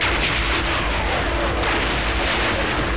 boomsnd.mp3